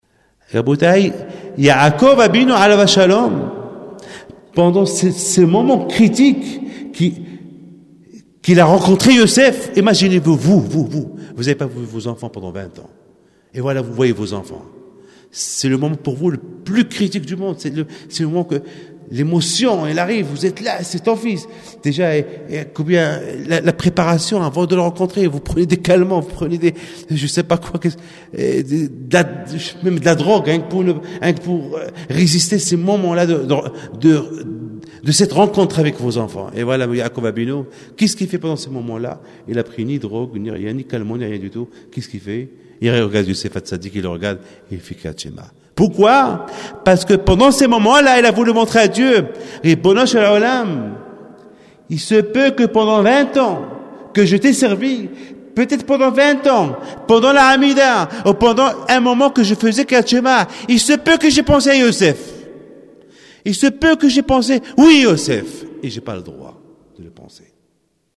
à la grande synagogue de la rue de la Victoire le 10 Shevath 5752 Parashath Beshala’h – 15 janvier 1992.